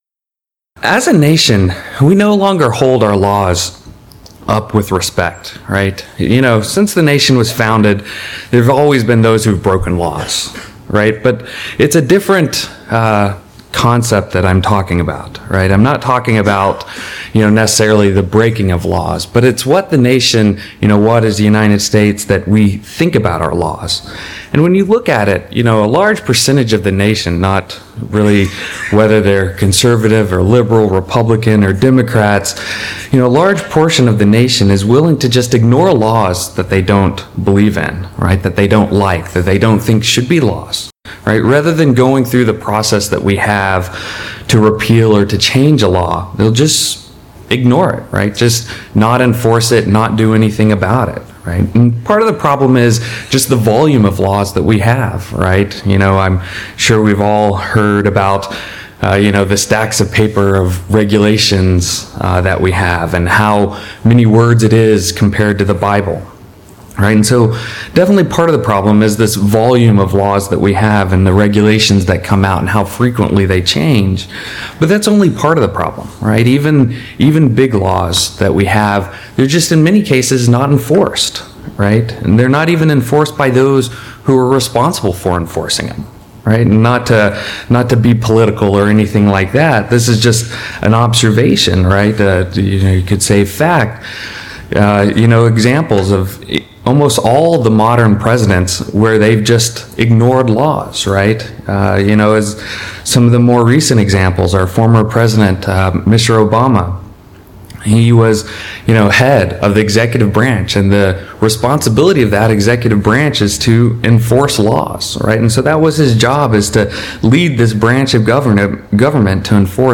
Given in Seattle, WA